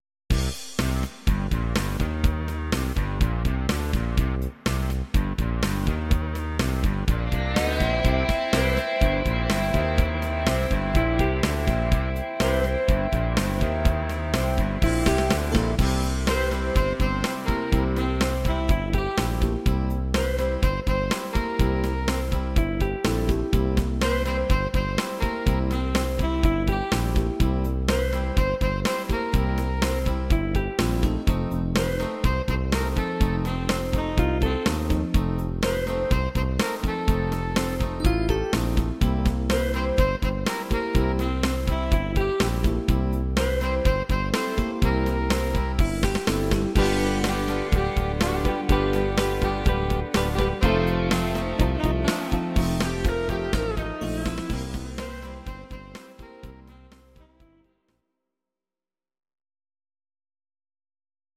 Audio Recordings based on Midi-files
cover